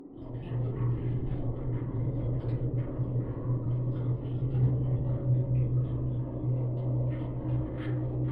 Призрачная атмосфера
prizrachnaya_atmosfera_dtn.mp3